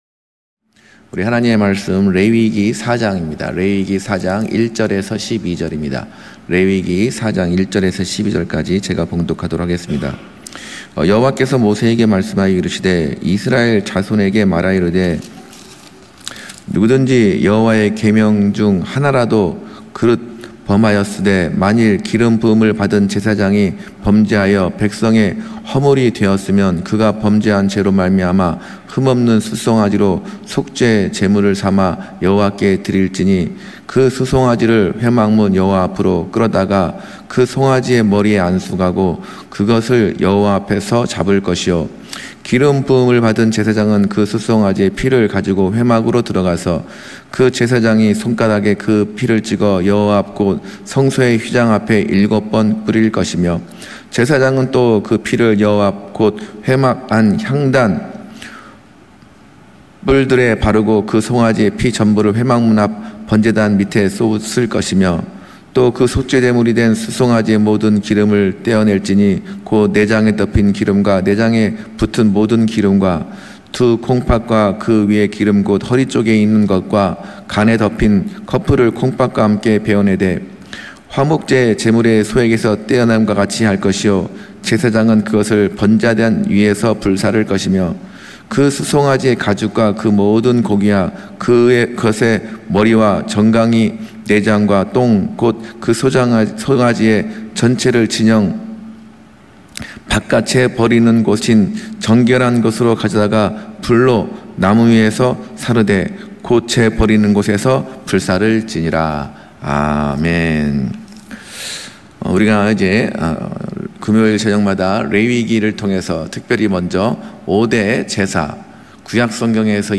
금요설교